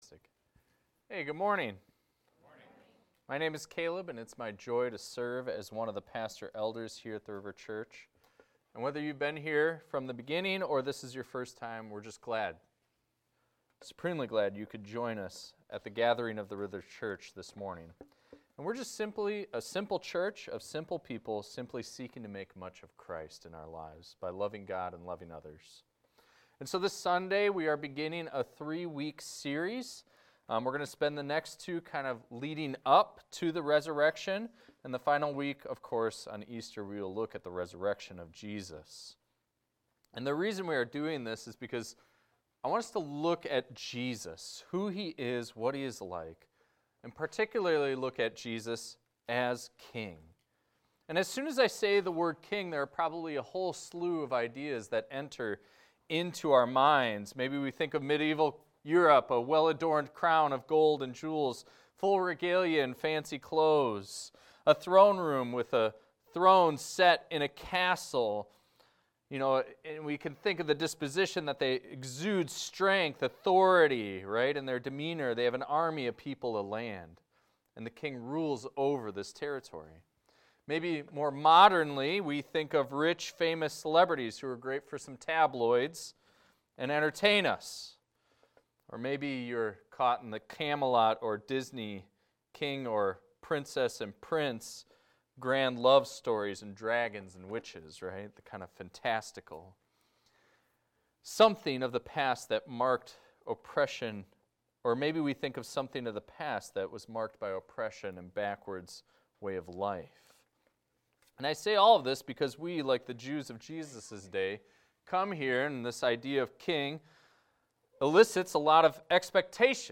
This is a recording of a sermon titled, "Mounted On A Colt."